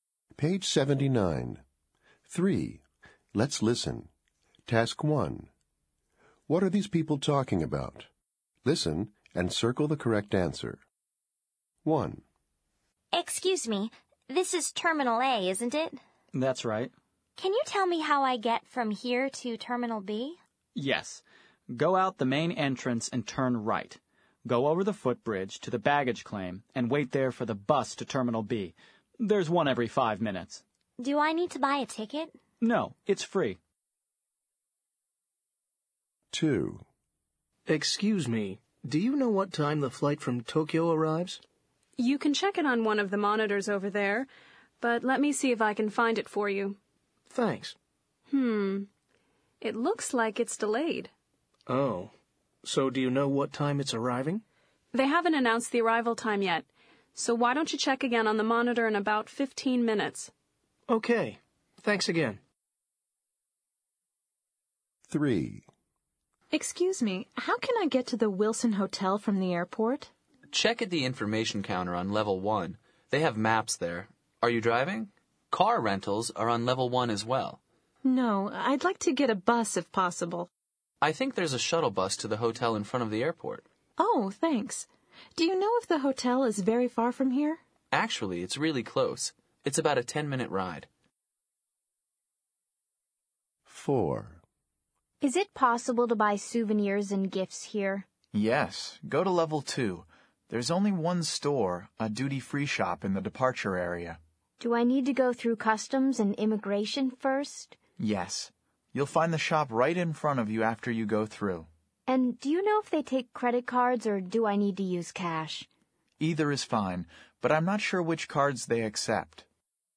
What are these people talking about?